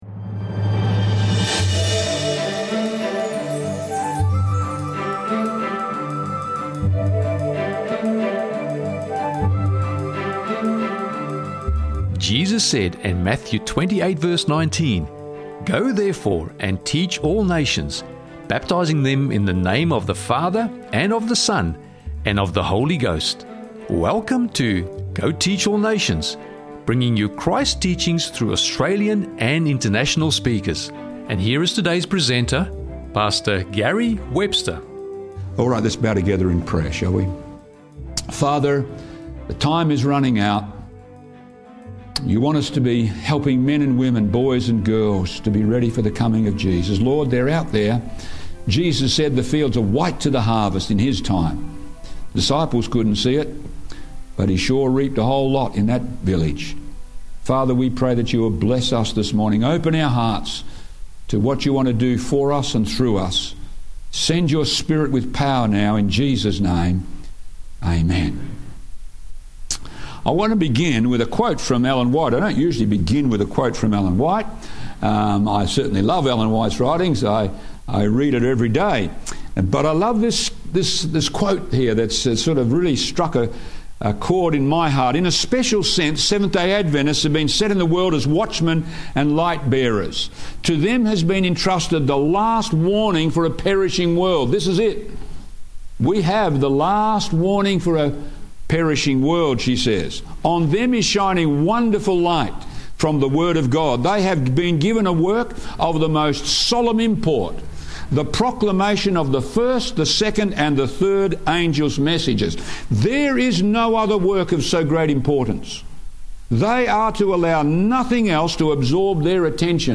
Sharing the Everlasting Gospel: A Call to Action - Sermon Audio 2549